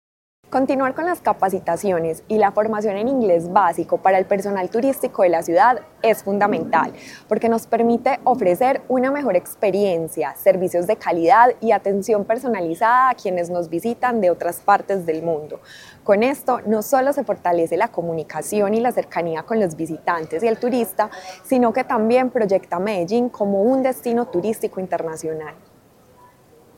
Declaraciones de la secretaria de Turismo y Entretenimiento, Ana María Mejía
Declaraciones-de-la-secretaria-de-Turismo-y-Entretenimiento-Ana-Maria-Mejia.mp3